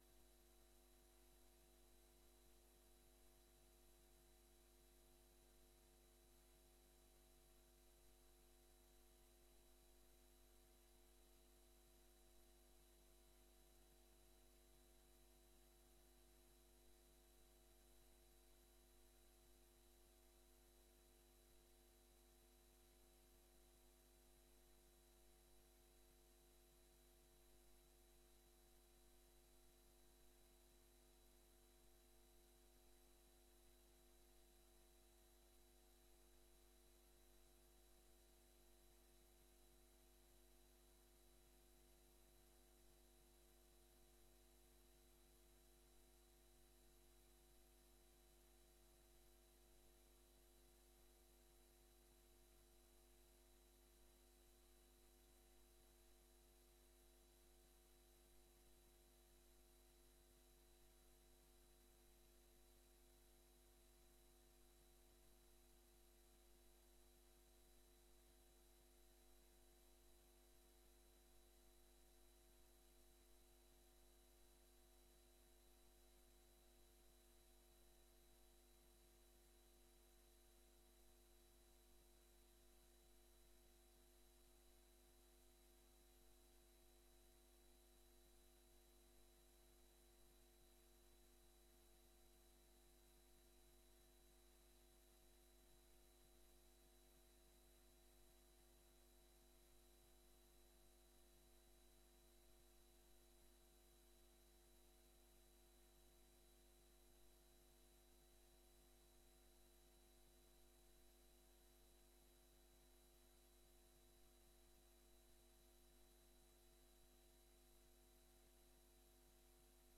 Download de volledige audio van deze vergadering
Locatie: Stadhuis